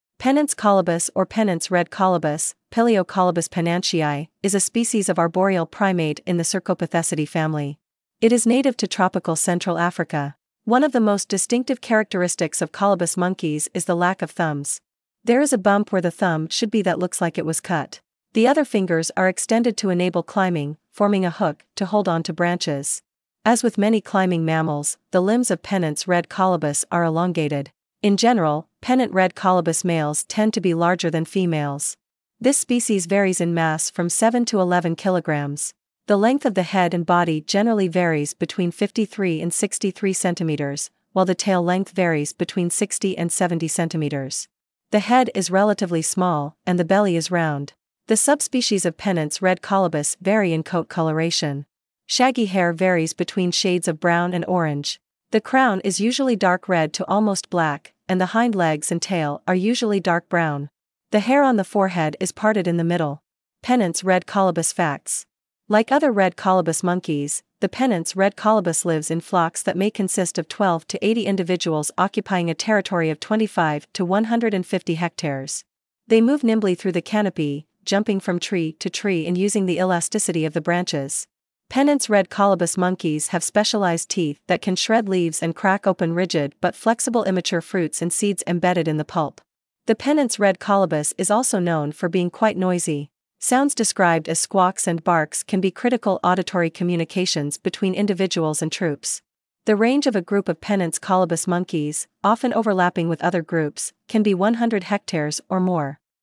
Pennant's Red Colobus
• The Pennant’s Red Colobus is also known for being quite noisy. Sounds described as “squawks” and “barks” can be critical auditory communications between individuals and troops.
Pennants-Red-Colobus.mp3